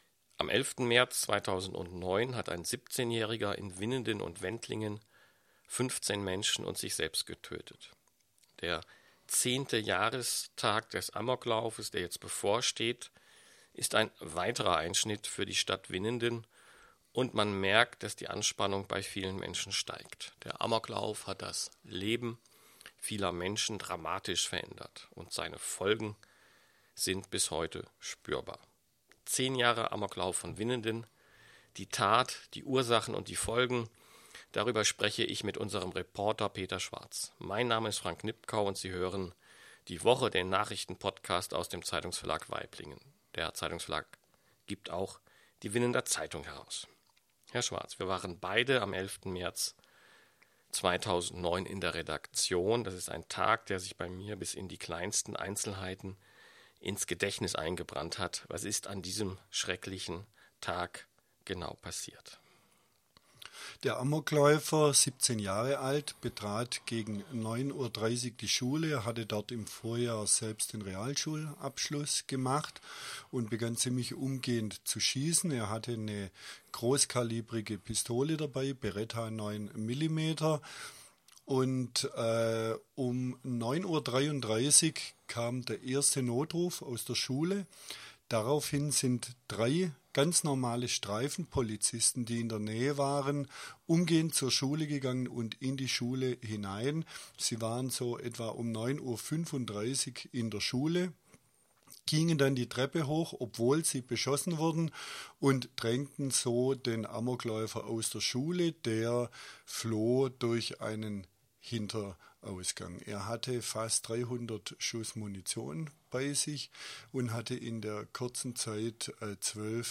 Am 11. März 2009 hat ein 17-Jähriger in Winnenden und Wendlingen 15 Menschen und sich selbst getötet 31 Minuten 28.11 MB Podcast Podcaster Die Woche Die Woche ist der Nachrichten-Podcast aus dem Zeitungsverlag Waiblingen.